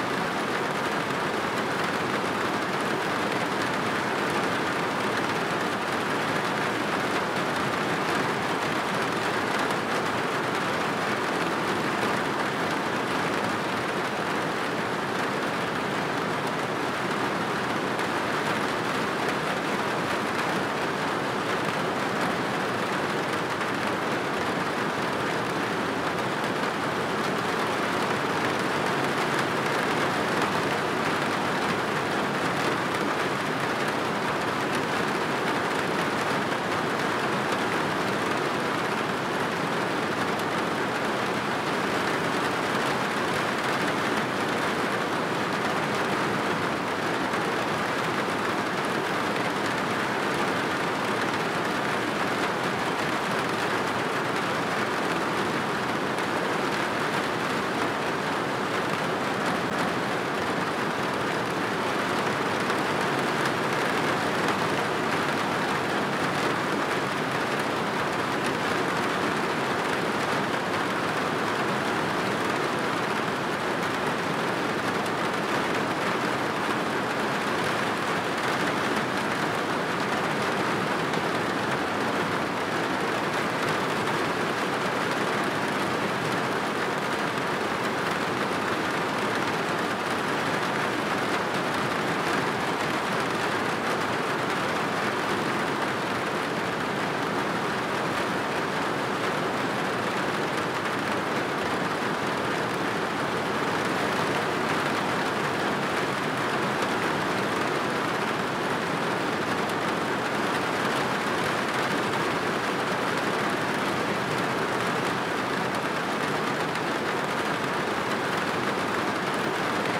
Rain Roof Loop.wav